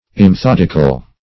Meaning of immethodical. immethodical synonyms, pronunciation, spelling and more from Free Dictionary.
Search Result for " immethodical" : The Collaborative International Dictionary of English v.0.48: Immethodical \Im`me*thod"ic*al\, a. Not methodical; without method or systematic arrangement; without order or regularity; confused.